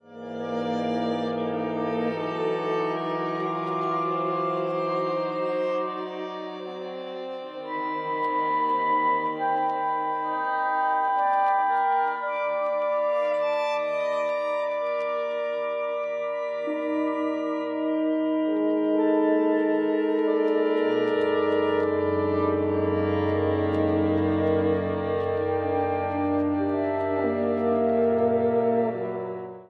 Beschreibung:Klassik; Ensemblemusik
Fl, Ob, Klar, Fag, Hr, 2 Vln, Br, Vcl, Kb